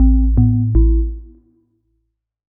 Melodic Power On 1.wav